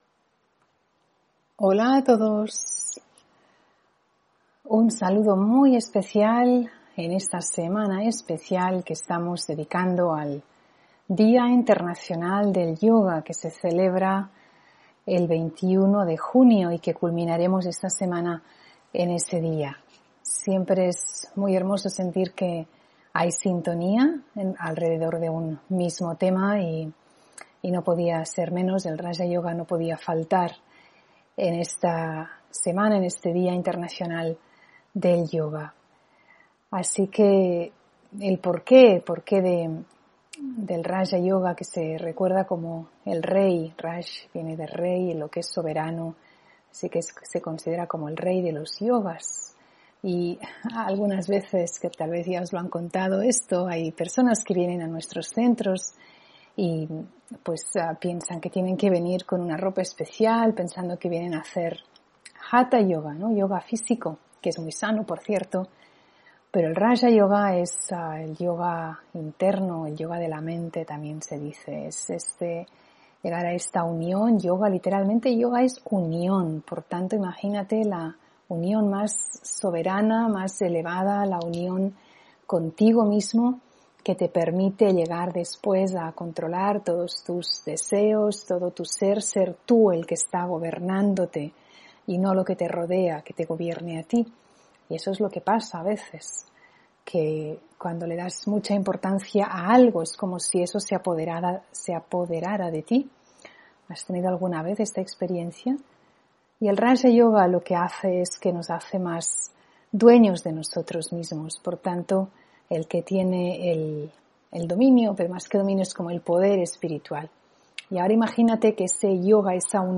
Meditación y conferencia: Raja Yoga para conocerme (16 Junio 2022)